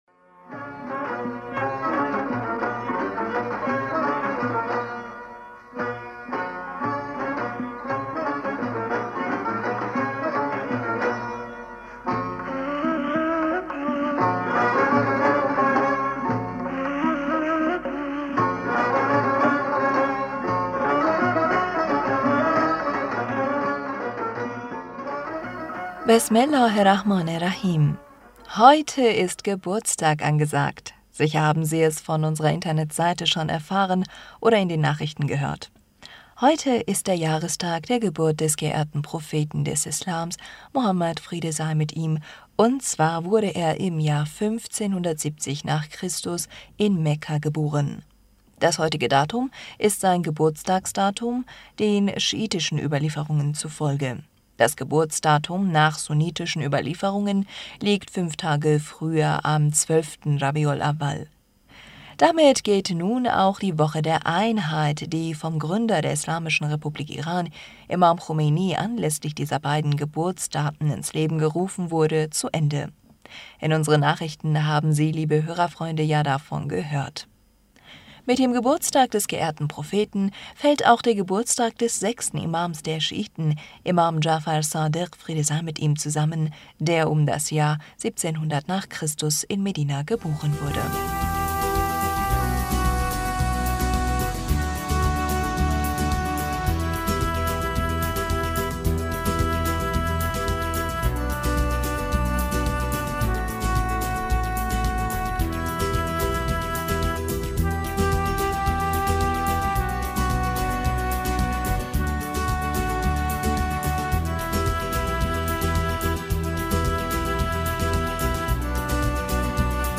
Hörerpostsendung am 24. Oktober 2021 Bismillaher rahmaner rahim - Heute ist Geburtstag angesagt, sicher haben Sie es von unserer Internetseite schon erfah...